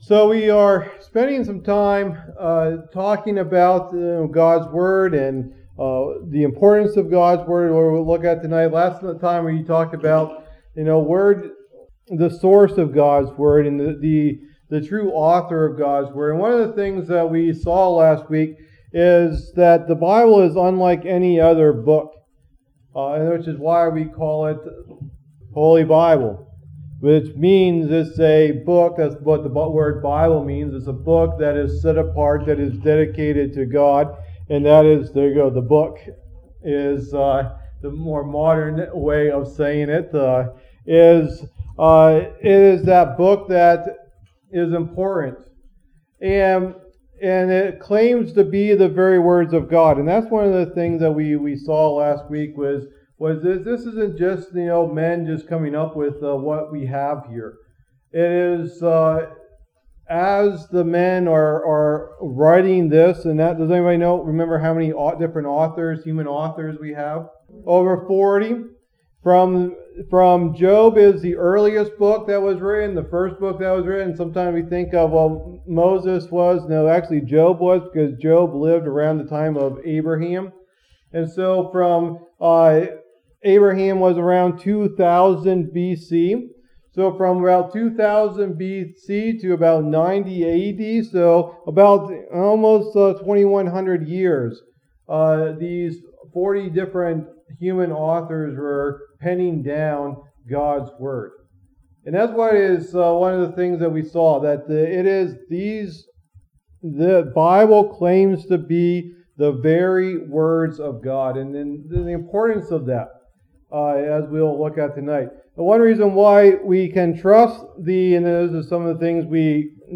Message #2 in the Apologetics Bible Study